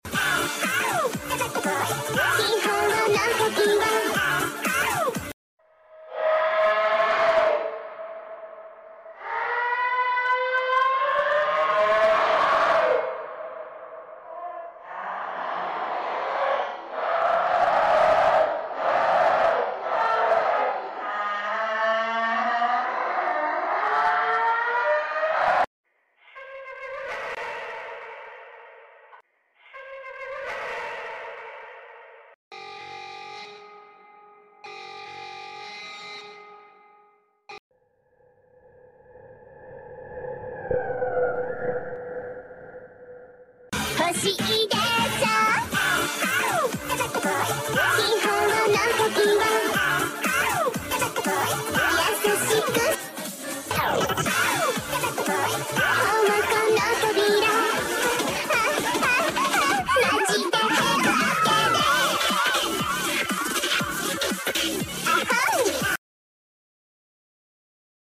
horror warning sound🔊 id boombox sound effects free download
horror warning sound🔊 id boombox roblox